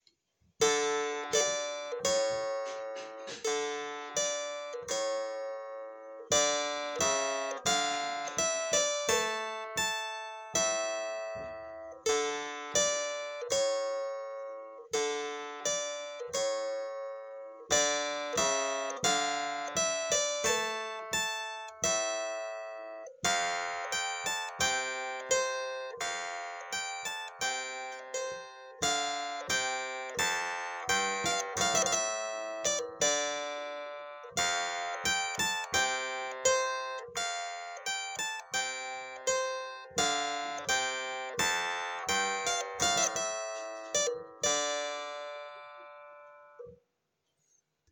And, of course, the clavichord! So there will be enough stuff left to keep me busy, especially with Ancient Music like this little piece written by Daniel Speer, a very versatile and interesting musician and author of the baroque era:
clavichord-speer-aria.mp3